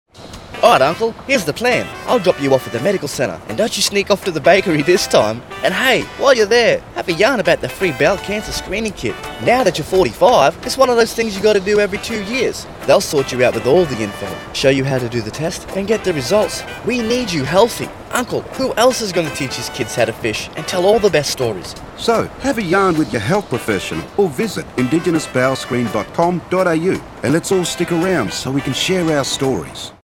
Using humour, pride in local culture, and Creole translations, we’ve created an engaging and accessible campaign.
Whether it’s sharing the importance of bowel cancer screening or reminding listeners about their health check-ups, we’ve made sure the tone reflects the spirit of the community—inviting, approachable, and relatable.